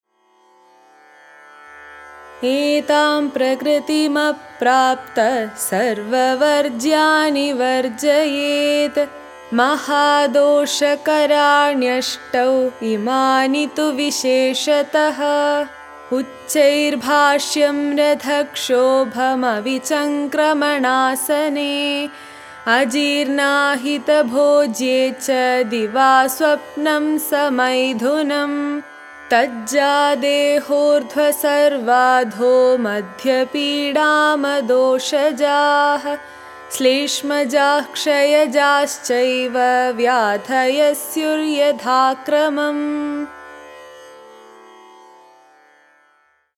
IMPORTANT SLOKA